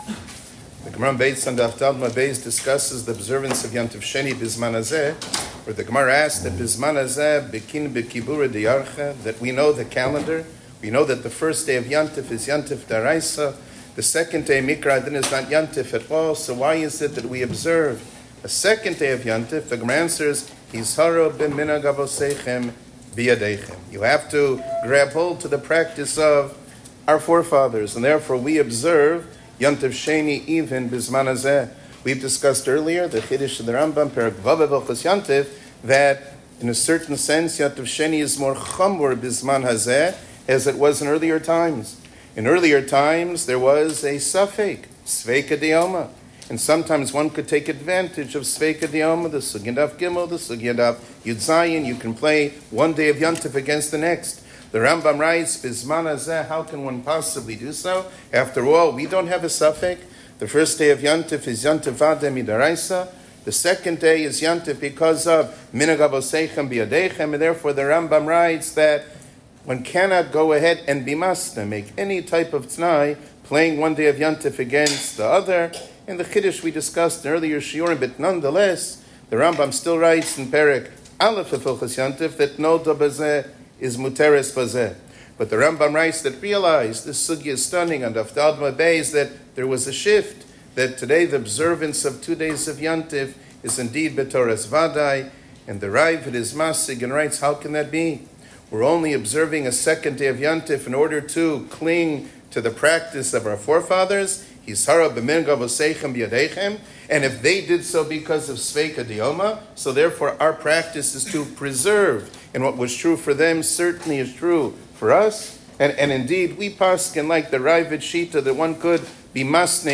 שיעור כללי - ברכה על מנהגים